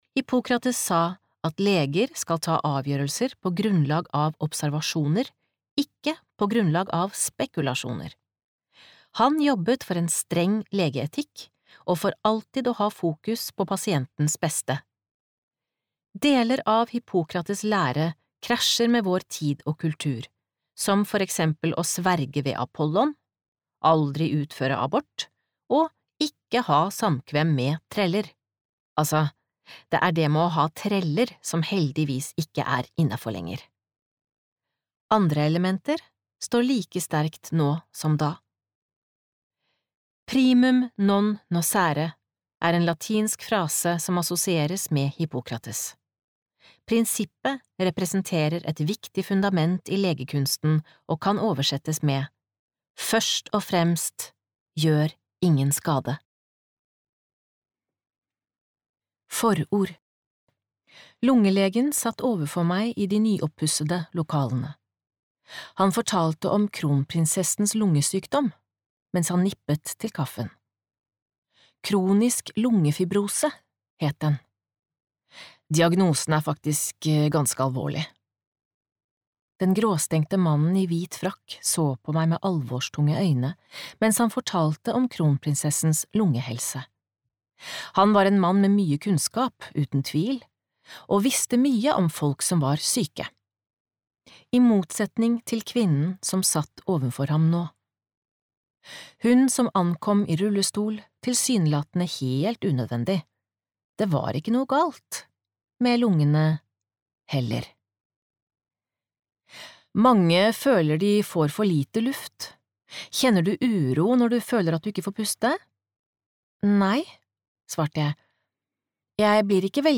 Gjør ingen skade - en bok om ME (myalgisk encefalopati) (lydbok